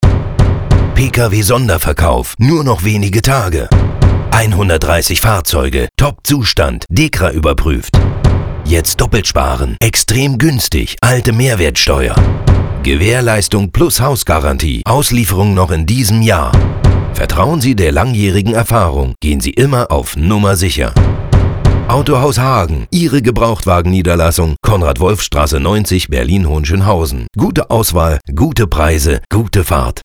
Radio: ALLE MAL HERHÖREN!